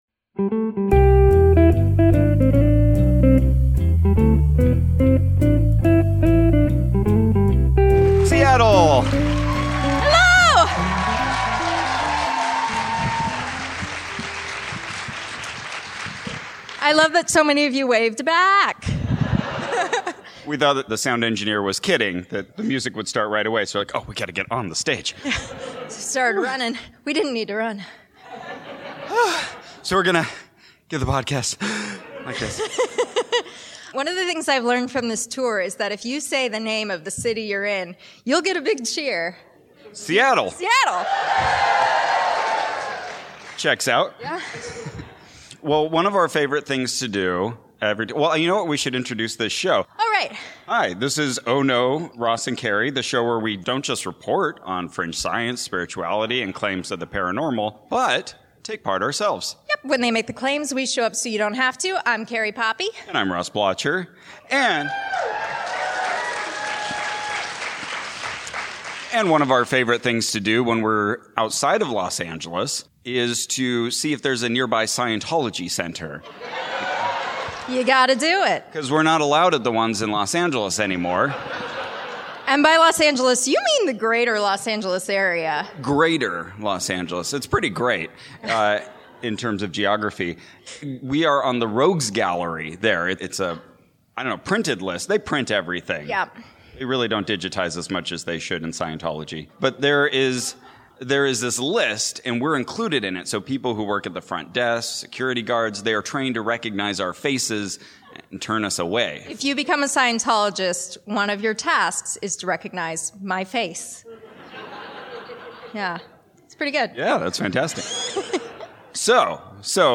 Live from PodCon Seattle